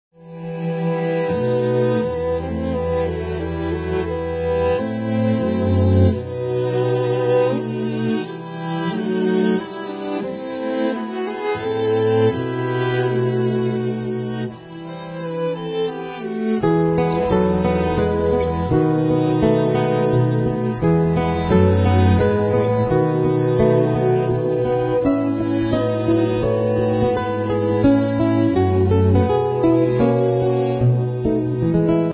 Classical guitar
Flute
Violins
Cello
Keyboards
Vocals
Marimba / Vibes
String-orchestra